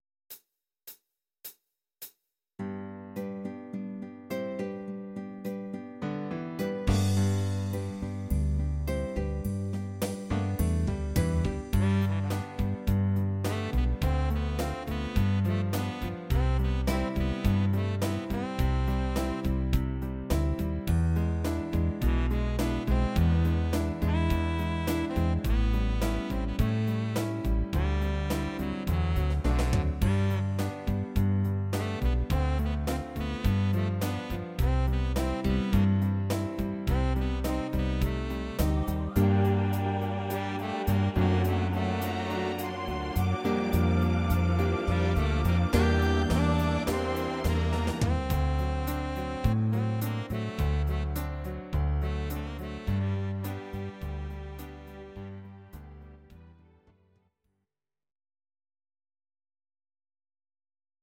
Audio Recordings based on Midi-files
Oldies, Country, 1950s